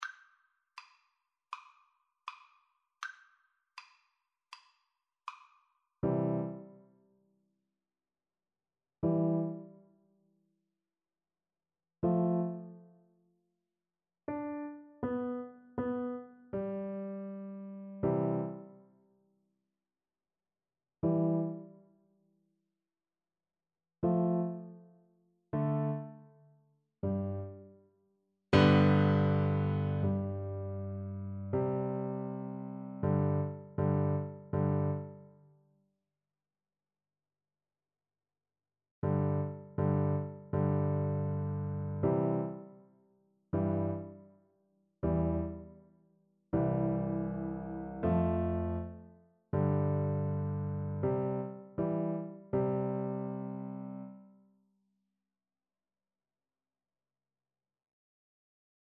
Free Sheet music for Piano Four Hands (Piano Duet)
Andante